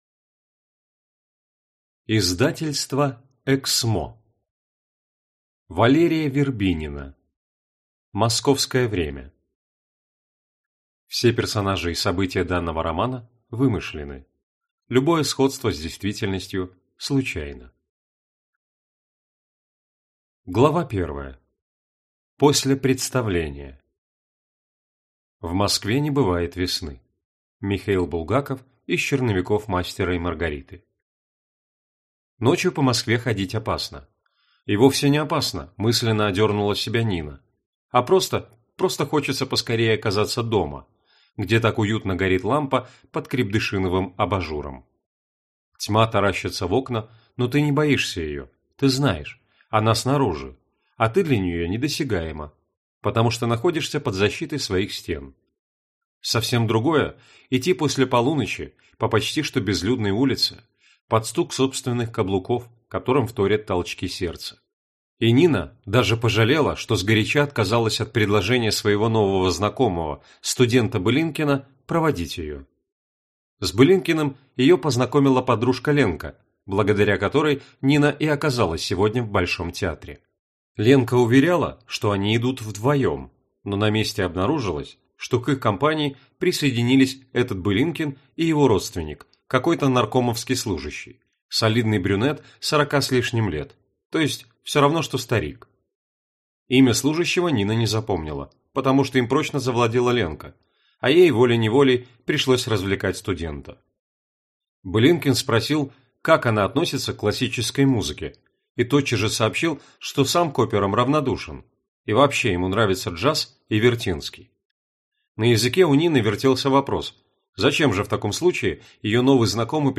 Аудиокнига Московское время | Библиотека аудиокниг